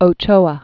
(ō-chōə), Severo 1905-1993.